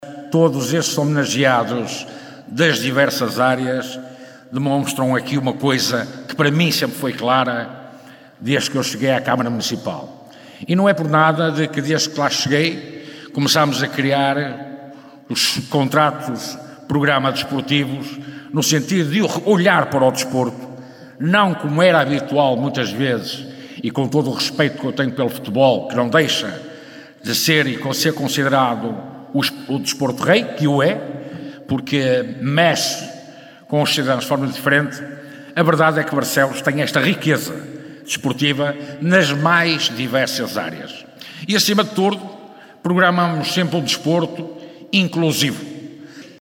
Miguel Costa Gomes, presidente do Município de Barcelos,  enalteceu o trabalho que tem sido feito no âmbito desportivo.
A II Gala do Desporto de Barcelos decorreu na noite deste domingo, no Pavilhão Municipal de Barcelos, com organização do Município de Barcelos.